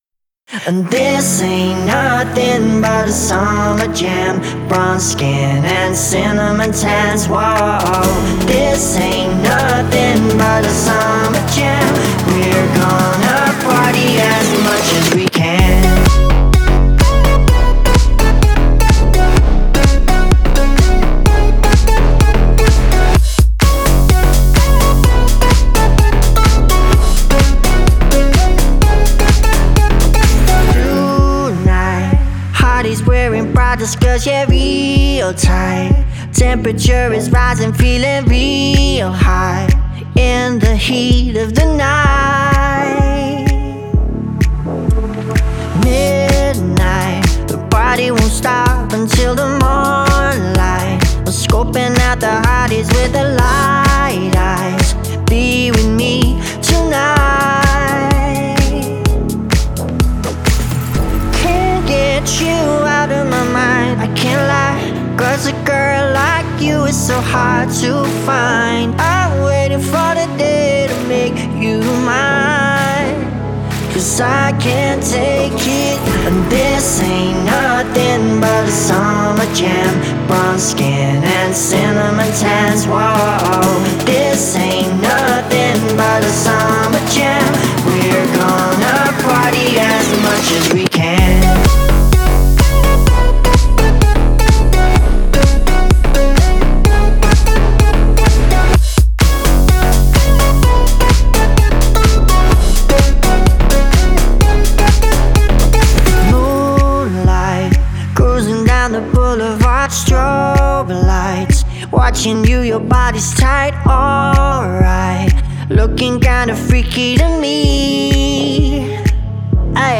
это яркий трек в жанре электронная музыка